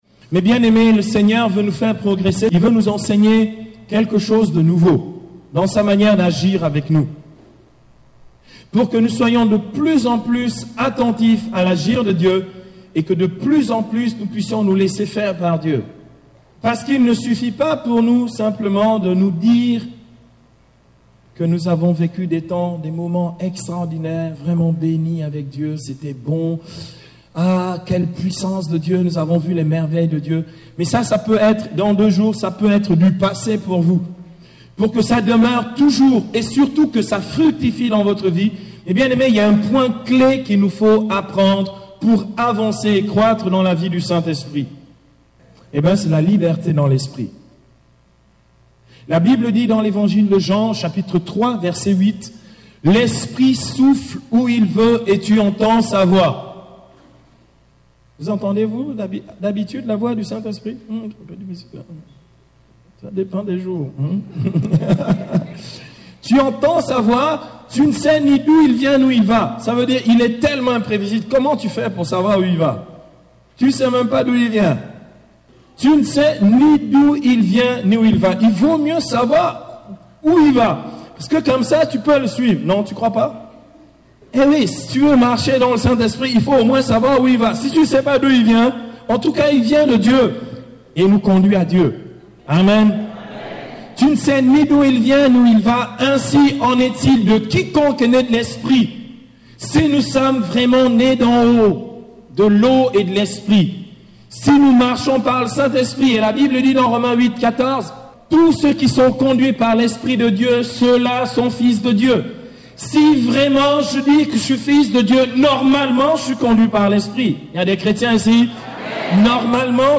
Enseignement
Format :MP3 64Kbps Mono